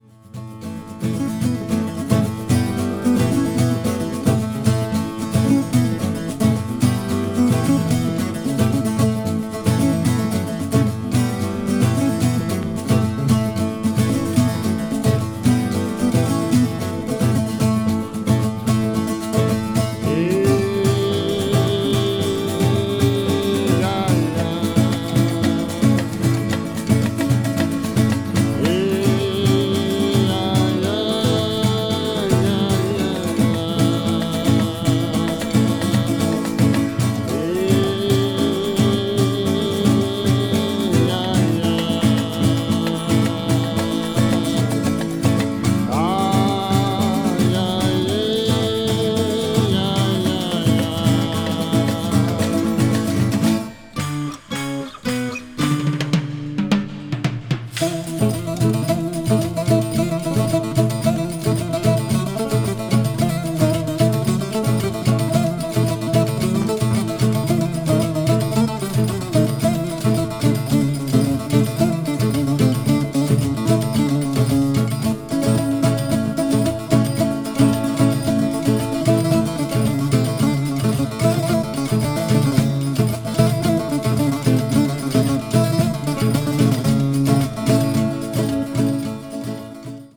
media : EX-/EX-(わずかにチリノイズが入る箇所あり,再生音に影響ない薄いスリキズあり)